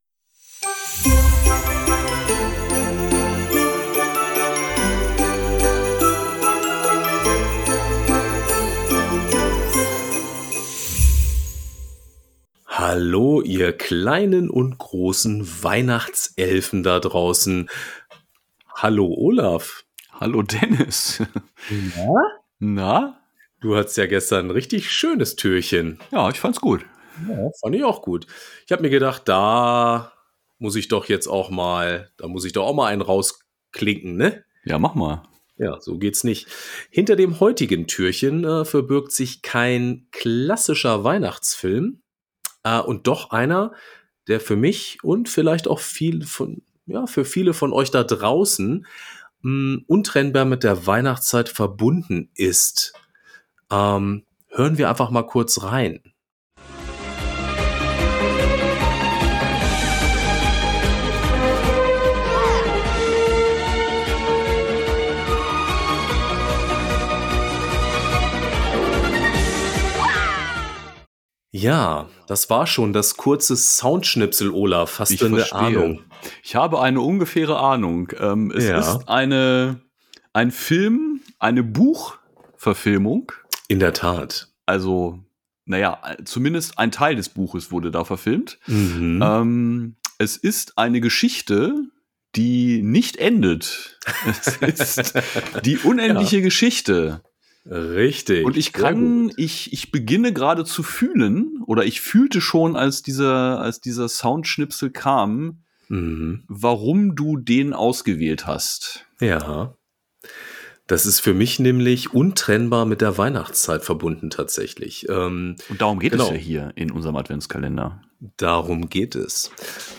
Ein Klang aus der „guten alten Zeit“ – und zwei Podcaster in